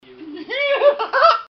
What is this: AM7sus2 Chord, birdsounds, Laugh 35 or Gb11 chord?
Laugh 35